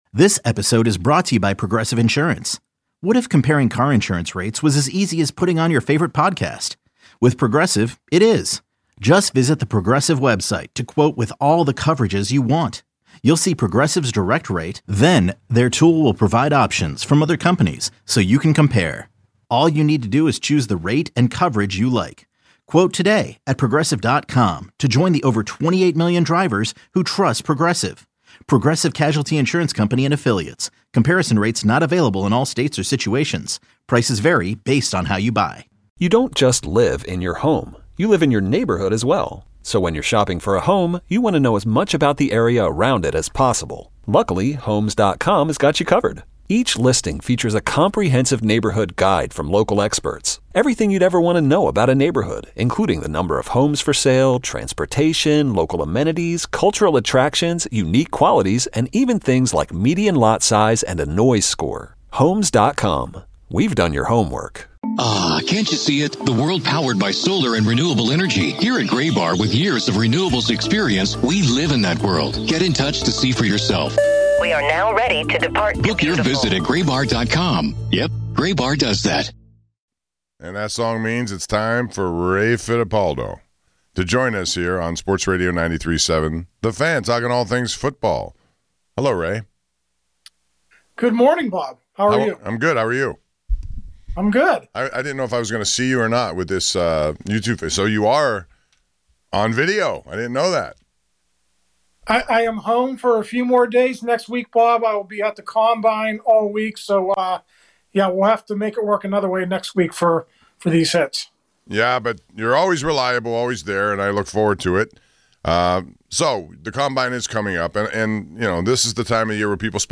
-Numerous callers chime in on the Steelers, George Pickens, T.J. Watt, and the Four Nations Faceoff. -Many more calls on the Penguins, Steelers, and Mike Tomlin.